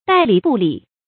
待理不理 注音： ㄉㄞˋ ㄌㄧˇ ㄅㄨˋ ㄌㄧˇ 讀音讀法： 意思解釋： 要理不理。